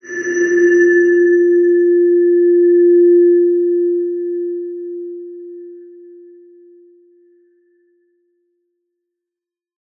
X_BasicBells-F2-ff.wav